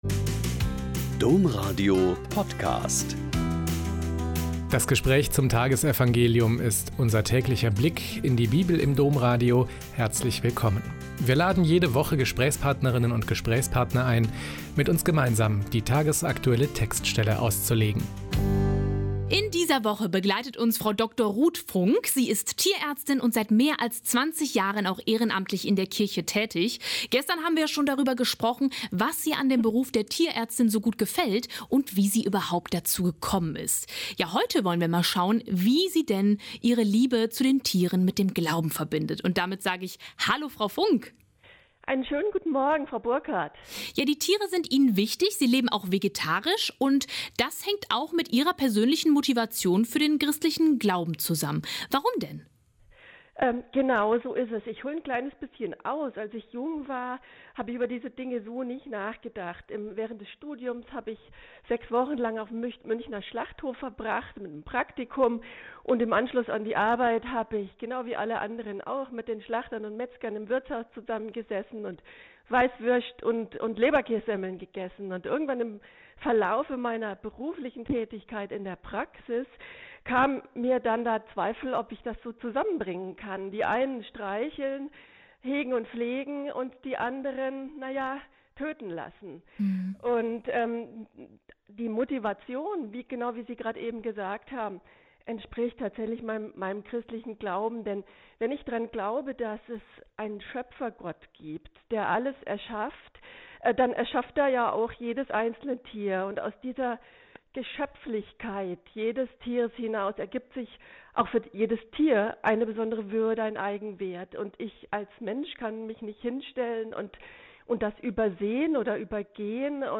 Mt 2,1-12 - Gespräch